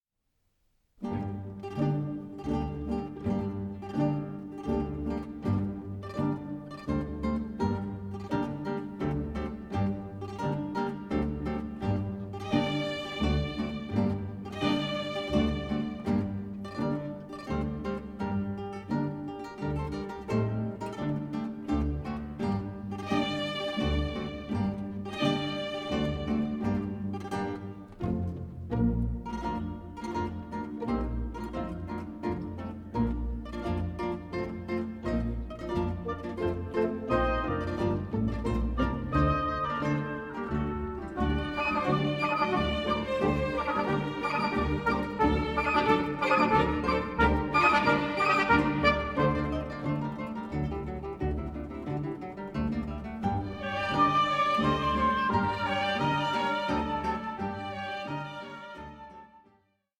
for 2 Guitars and Orchestra
Allegro vivace